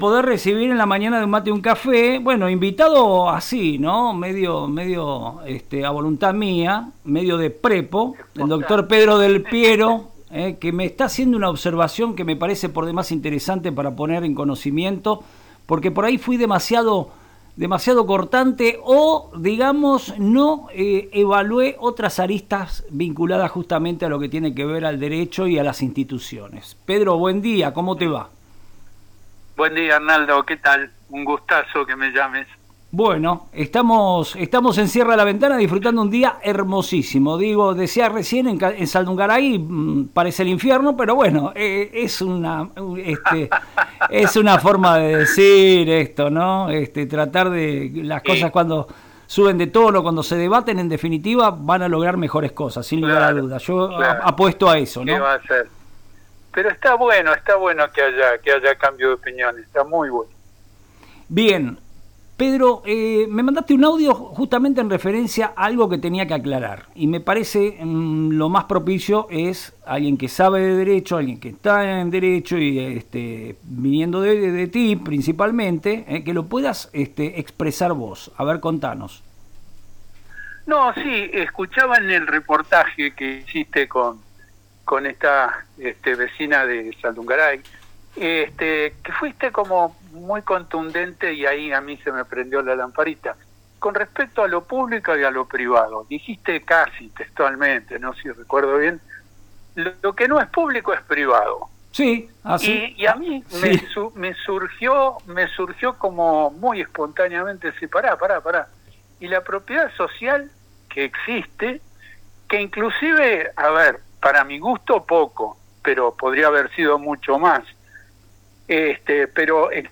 El ex senador nacional y especialista en derecho dialogó con FM Reflejos sobre la importancia de las cooperativas y las organizaciones comunitarias en la gestión de bienes patrimoniales.